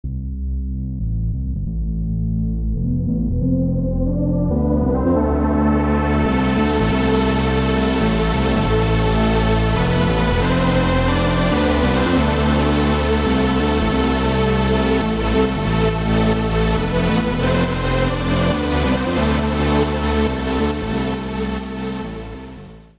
Хм, не знаю чо там, но вот v-station Качество mp3 деградировало, т.к. перекодировано дважды... но общий характер ясен Это вроде софтовый native вариант. Типичный virus по звуку, конкретно в этом примере. Вложения novation_v-station_.mp3 novation_v-station_.mp3 360,5 KB